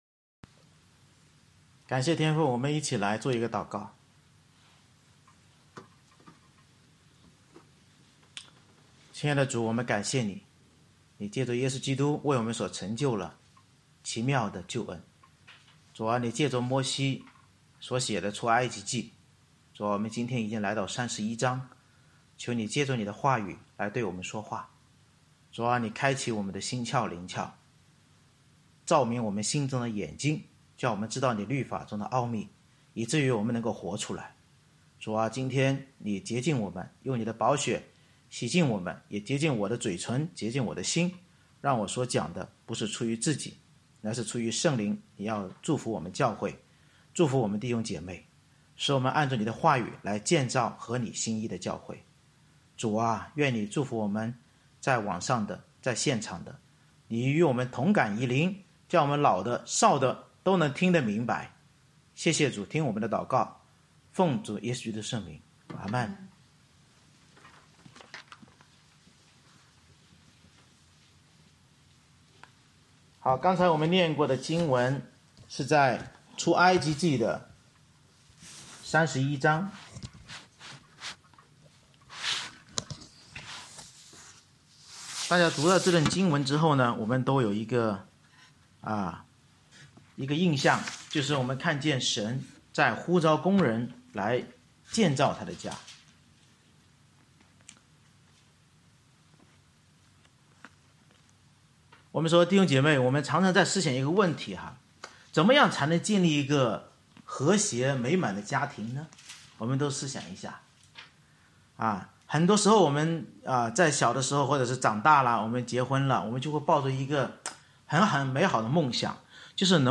November 29, 2020 新生活——成功建造神家之根源 Series: 《出埃及记》讲道系列 Passage: 出埃及记31章 Service Type: 主日崇拜 摩西按启示拣选工人遵行圣日并领受神指头所写十诫才能完成会幕工作，教导我们依靠主拣选差派工人遵行主日并领受神在基督身上大能启示才能成功建立教会。